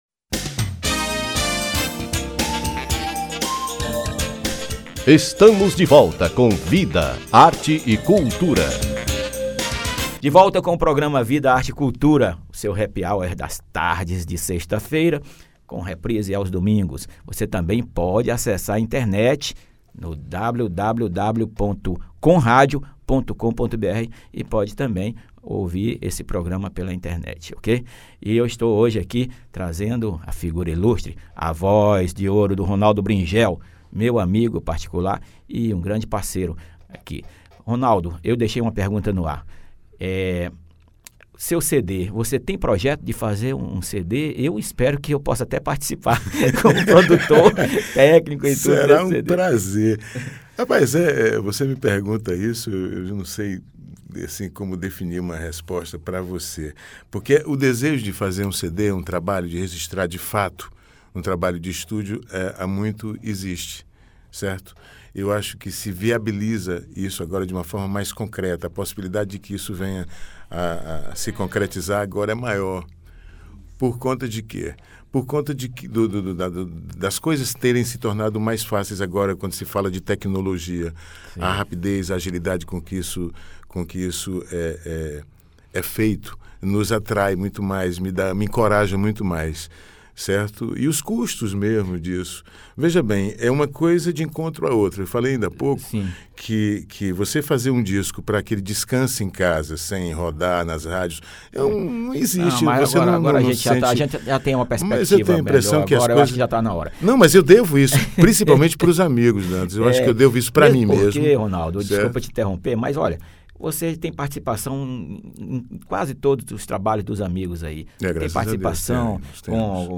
Entrevista (PODCAST)